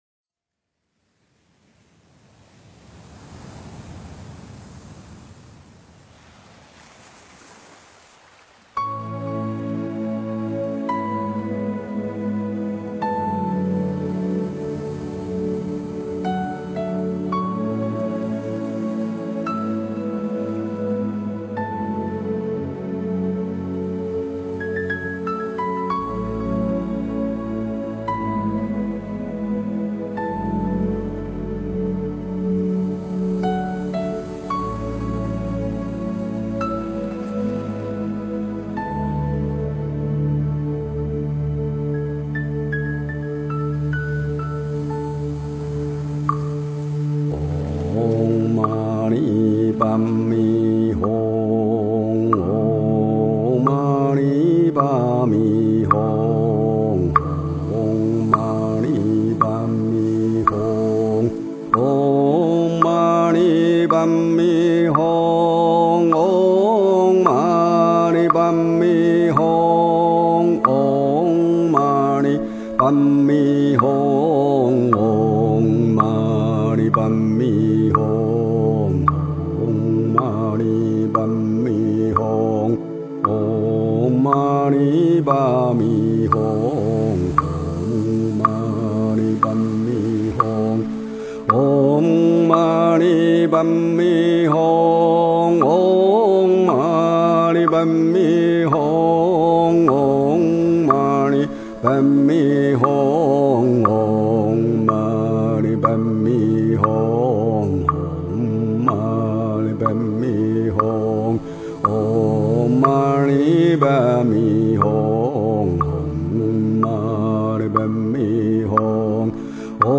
诵经
佛音 诵经 佛教音乐 返回列表 上一篇： 观音圣号(梵文修持版